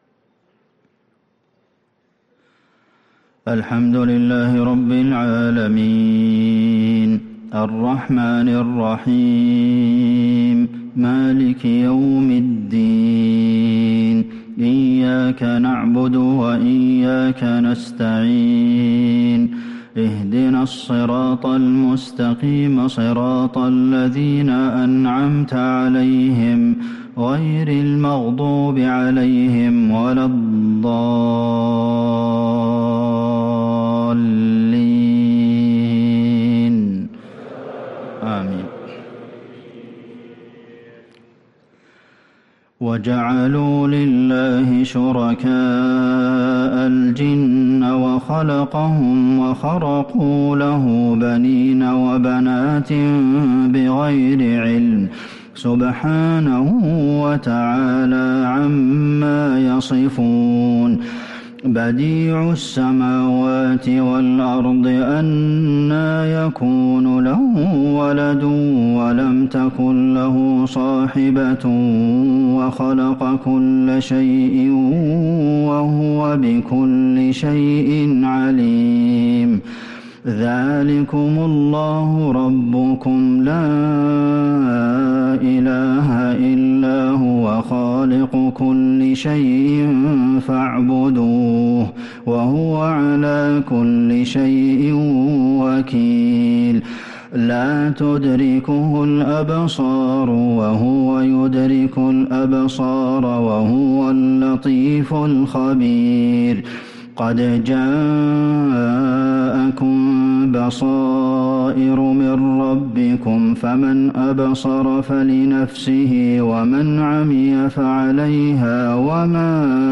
عشاء الجمعة 7 محرم 1444هـ من سورة الأنعام | Isha prayer from Surah Al-An’aam 5-8-2022 > 1444 🕌 > الفروض - تلاوات الحرمين